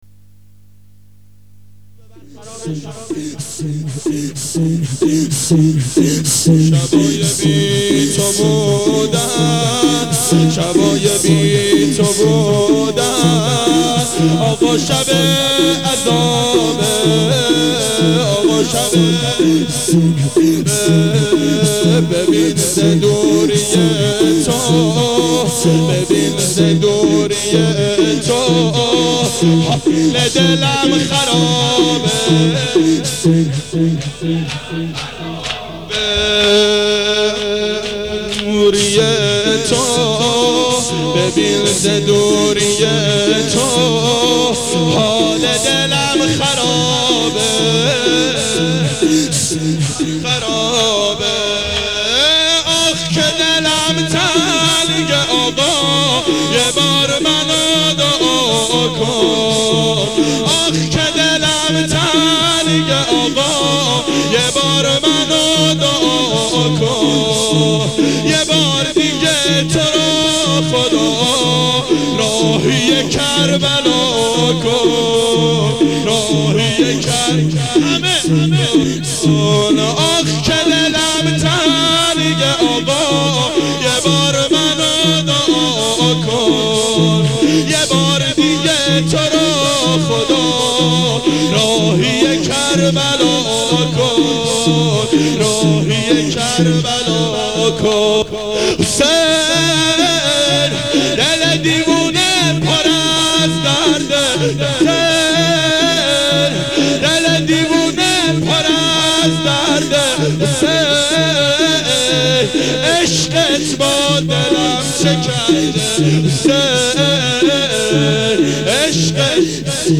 گلچین مداحی